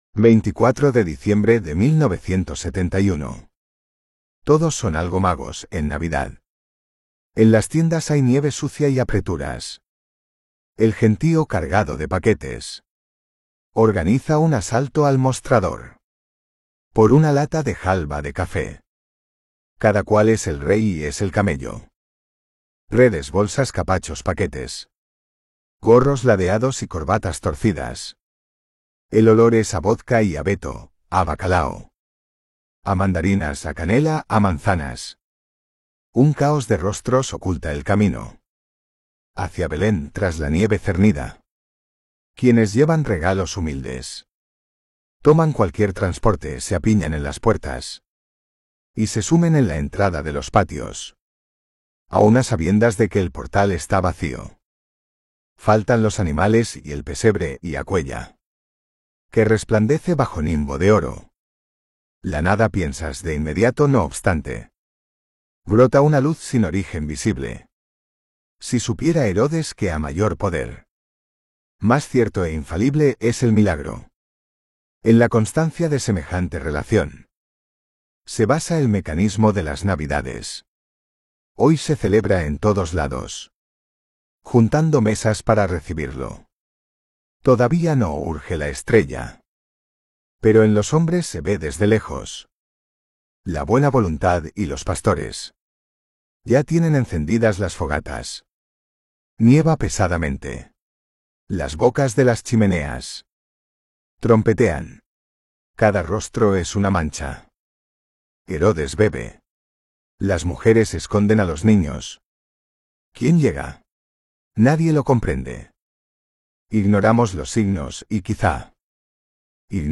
Poemas-selectos-Audiolibro.m4a